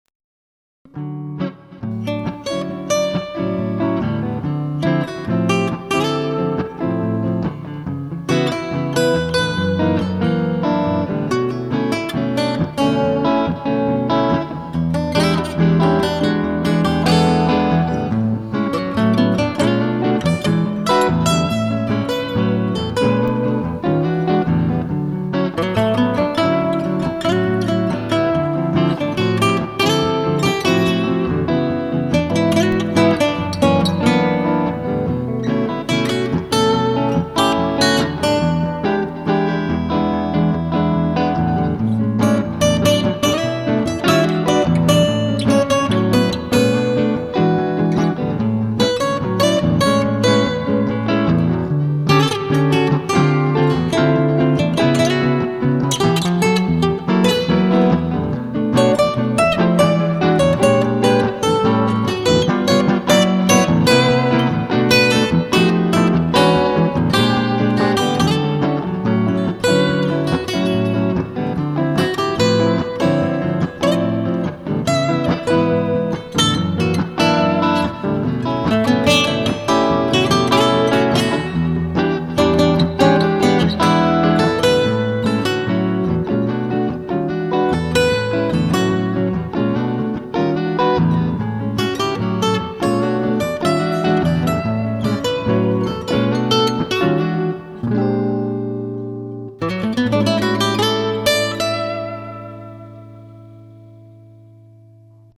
ich bin mit meiner Selmer-Kopie auf der rechten Seite.